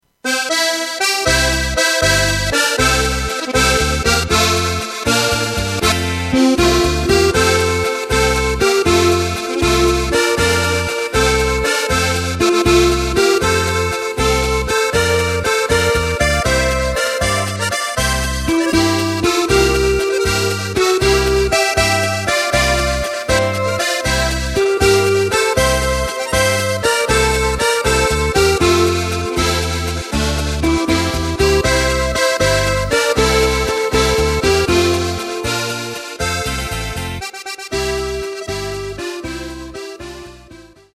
Takt:          3/4
Tempo:         237.00
Tonart:            F
Flotter Walzer aus dem Jahr 1986!